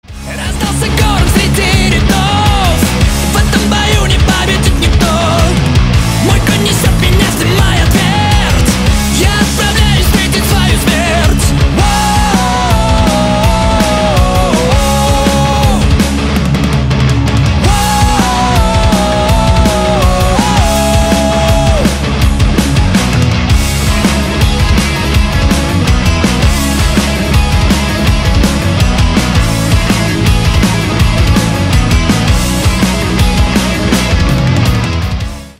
Рок Металл
кавер